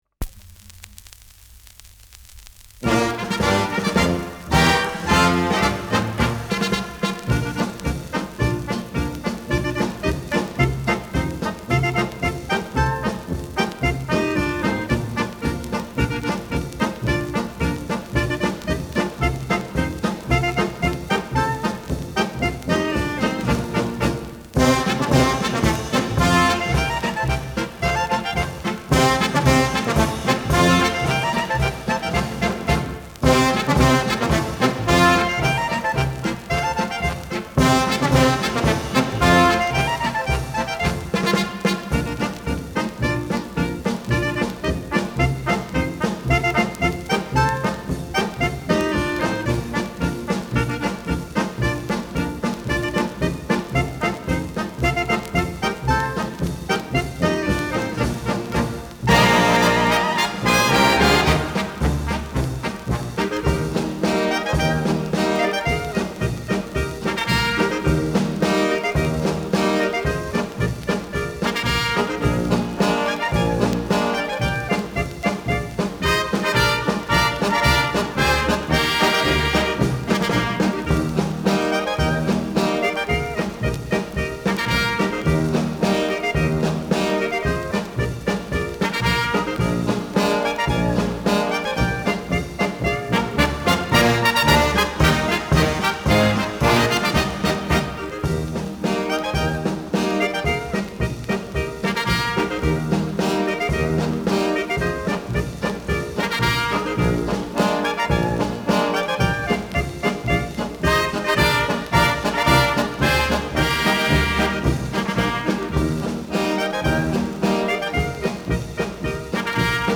Schellackplatte
Leicht abgespielt : Vereinzelt leichtes Knacken